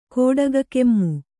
♪ kōḍaga kemmu